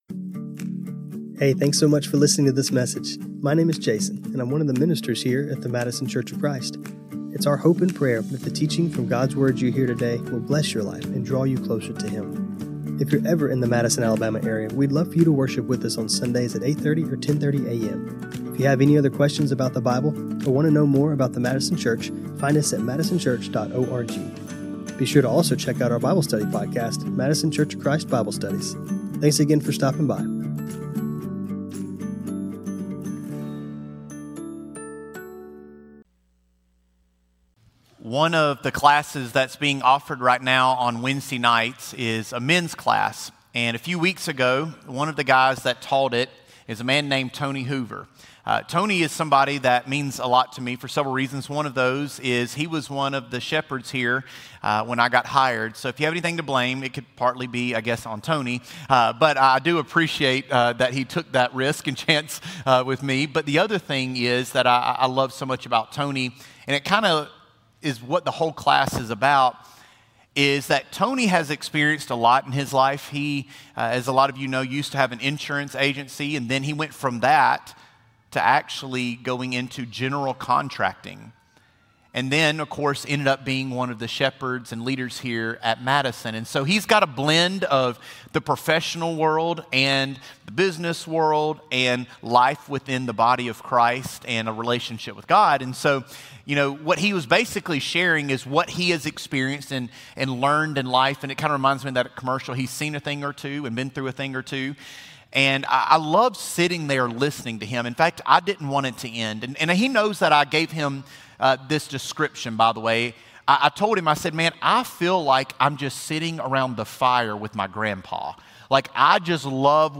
Isaiah 61:1-3 John 14:26-27 John 15:1-5 This sermon was recorded on Feb 15, 2026.